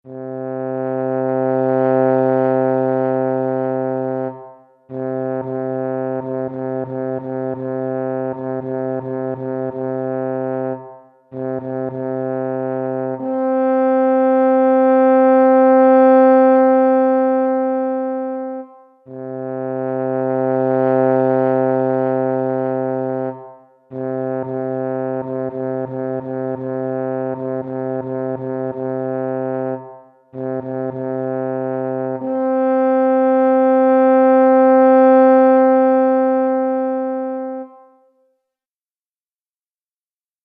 sygnał przeznaczony do grania na drewnianym rogu tucholskim Tytuł utworu